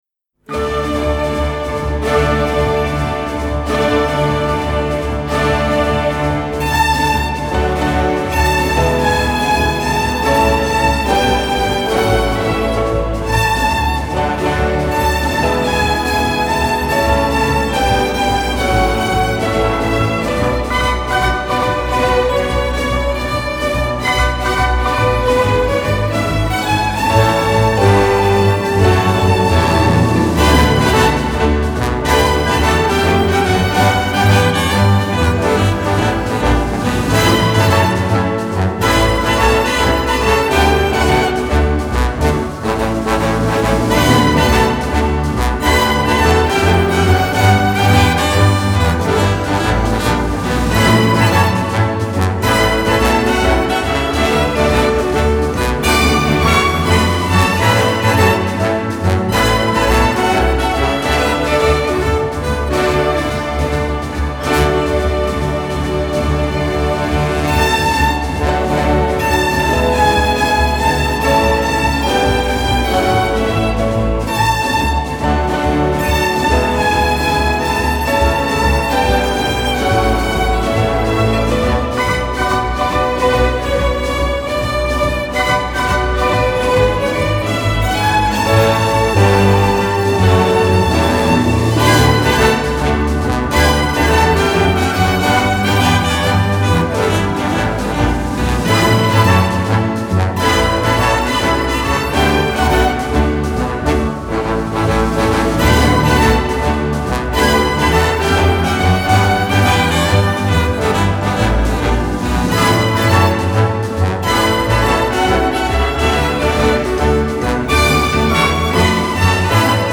Genre: Pop, Instrumental, Easy Listening